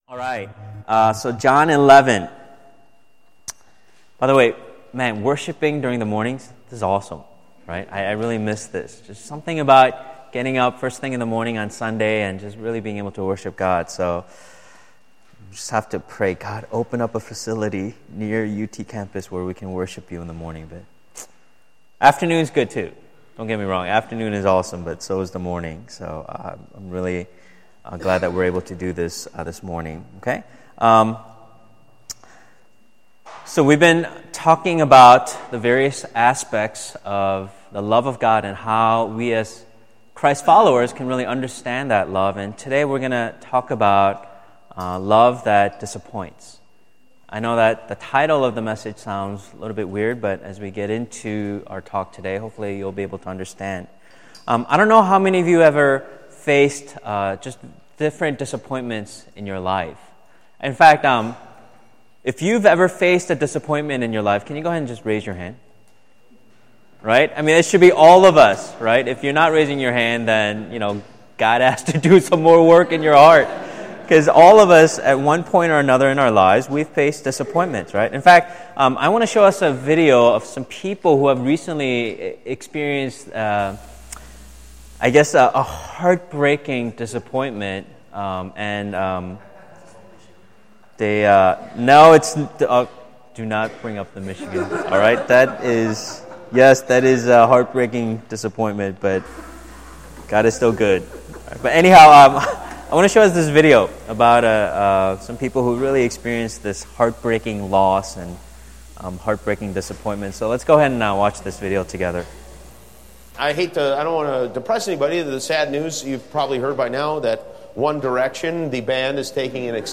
Undergraduate Retreat 2015: Grounded in Love Service Type: Sunday Celebration « Undergraduate Retreat 2015